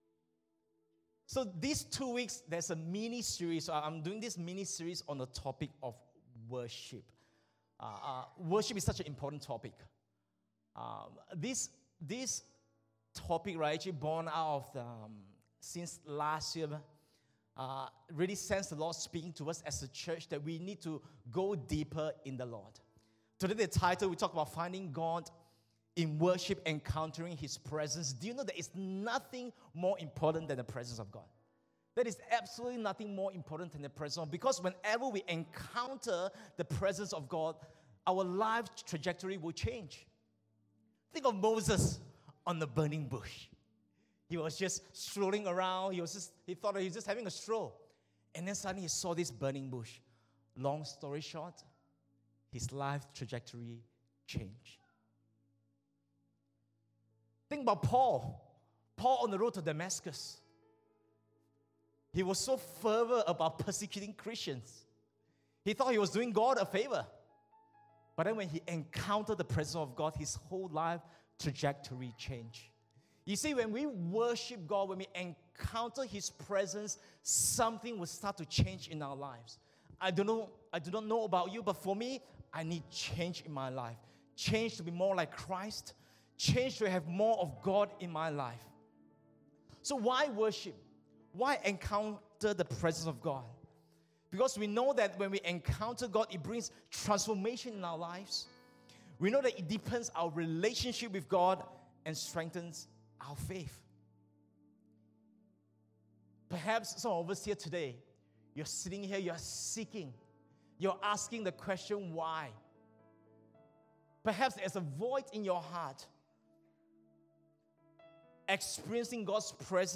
English Worship Service 30 July 2023